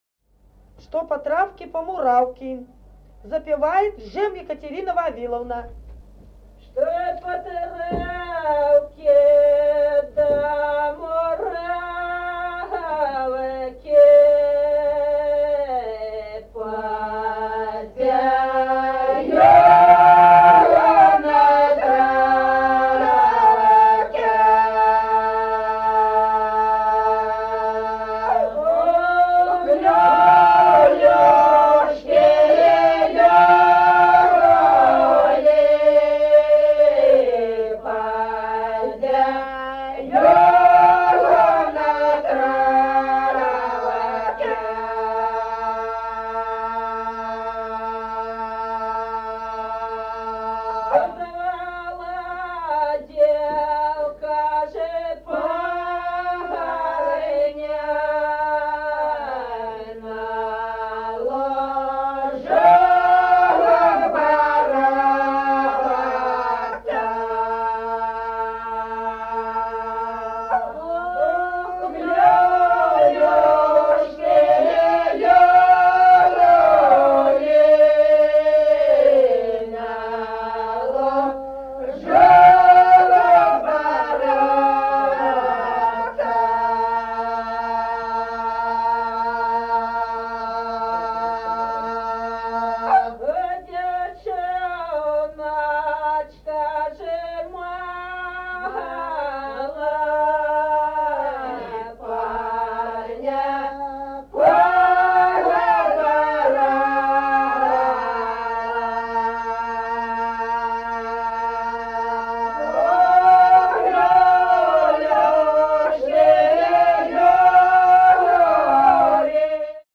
Песни села Остроглядово. Что по травке да по муравке.